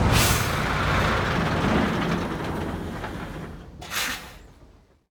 train-engine-stop-1.ogg